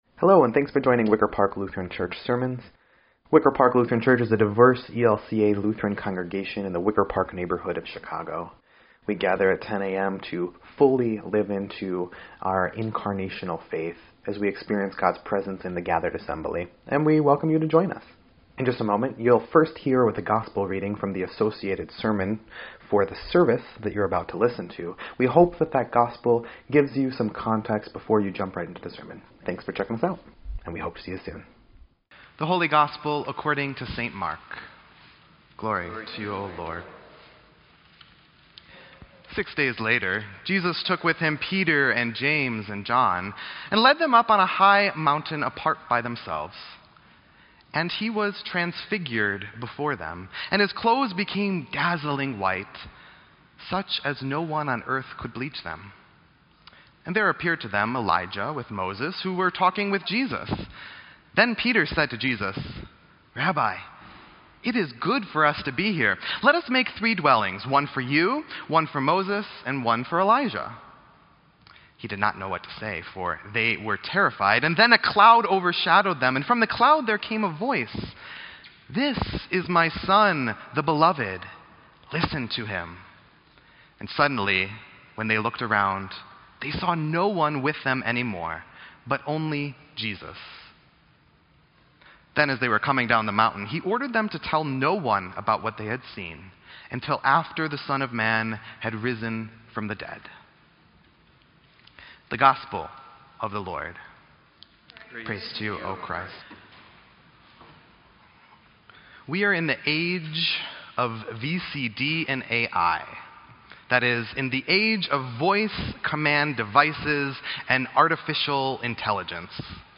Sermon_2_11_18_EDIT.mp3